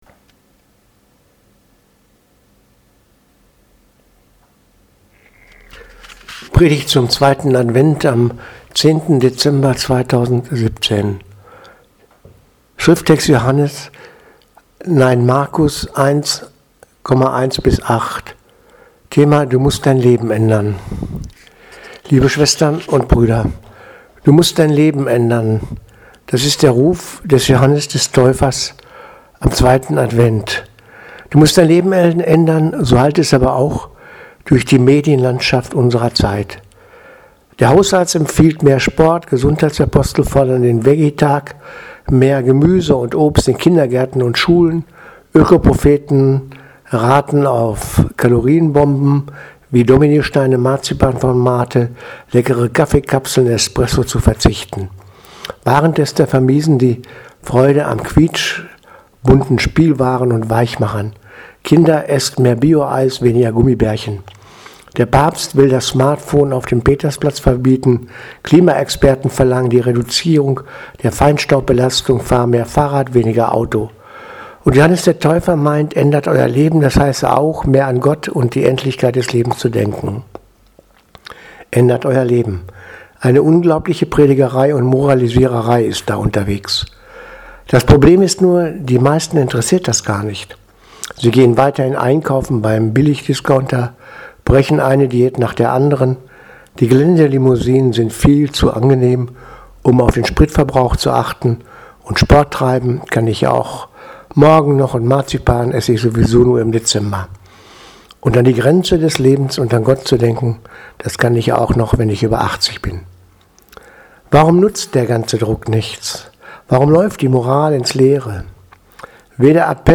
Predigt vom 10.12.2017 2.Advent